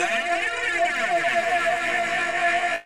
Tech Step 1 Effect.WAV